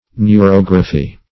neurography - definition of neurography - synonyms, pronunciation, spelling from Free Dictionary
Search Result for " neurography" : The Collaborative International Dictionary of English v.0.48: Neurography \Neu*rog"ra*phy\, n. [Neuro- + -graphy.]